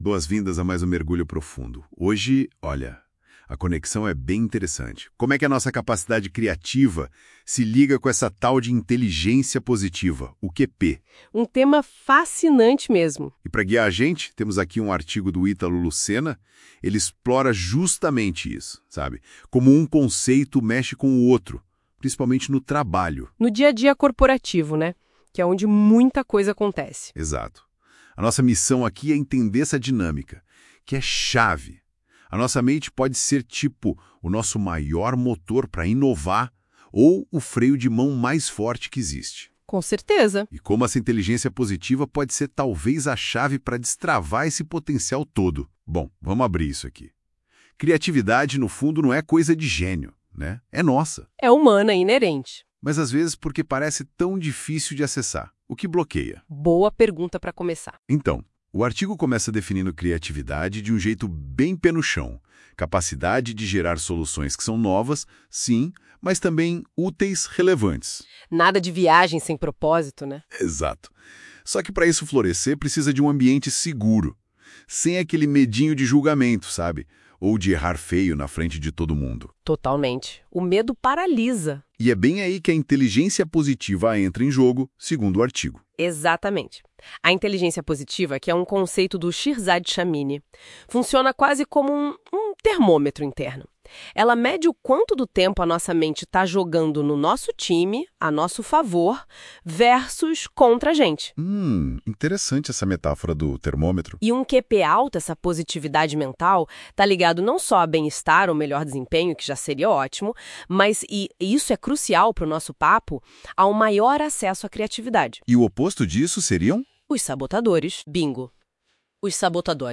Uma conversa aprofundada sobre os conceitos que você aprenderá neste curso.